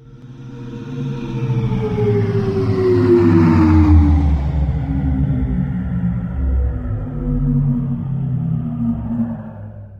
batterydrain.ogg